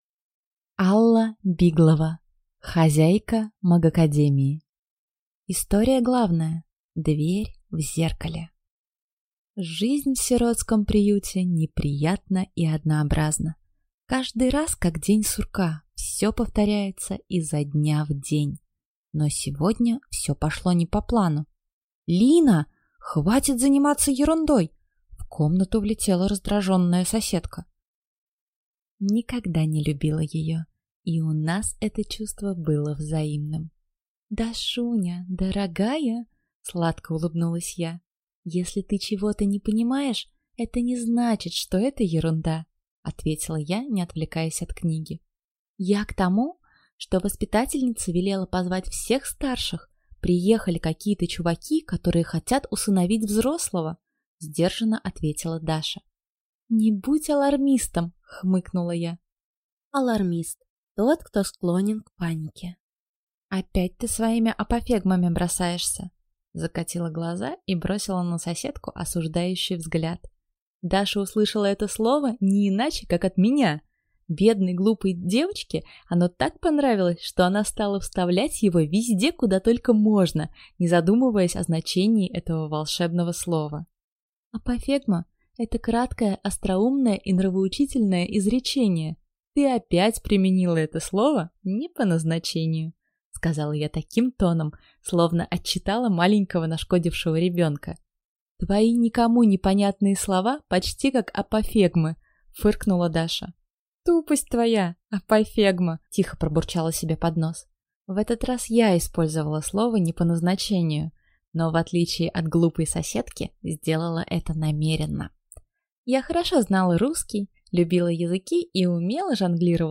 Аудиокнига Хозяйка магической академии | Библиотека аудиокниг